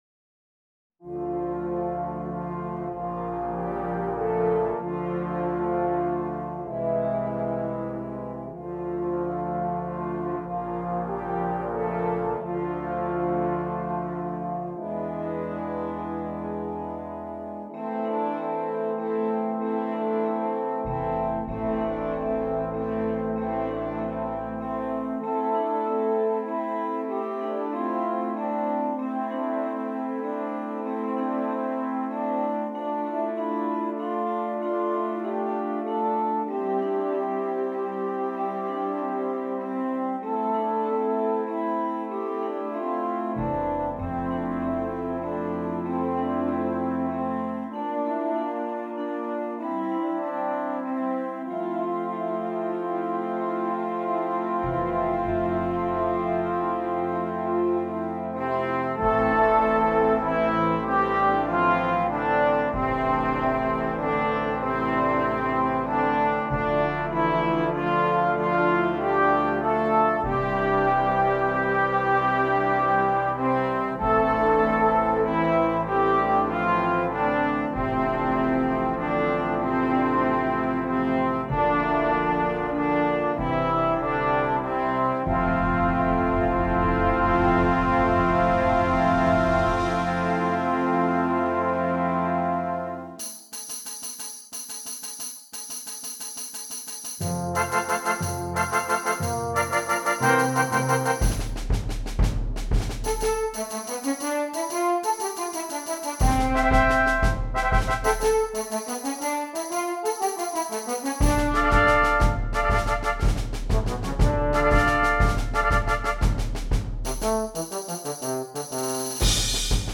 Brass Choir
Traditional